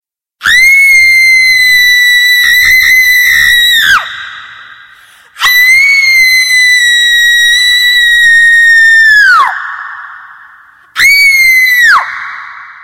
Ses Efektleri